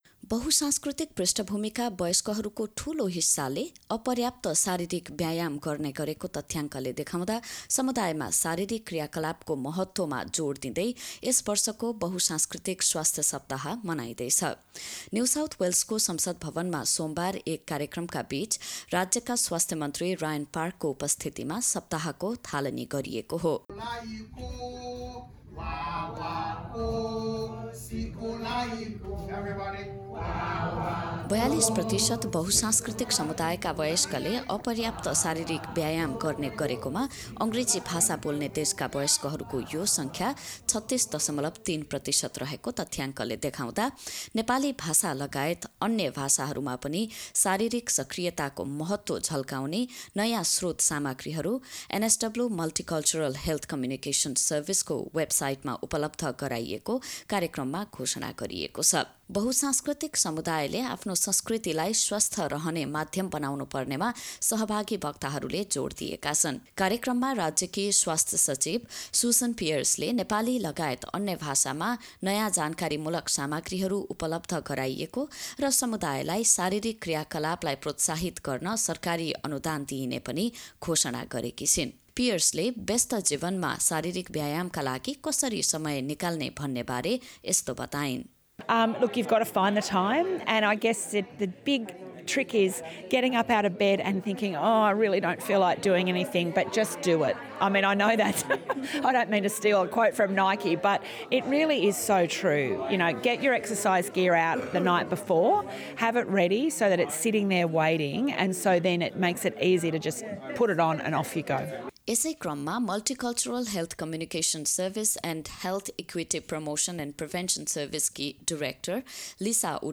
The event launched at NSW Parliament House on Monday featured an announcement about grants of up to $2,000 available for 15 organisations. A report.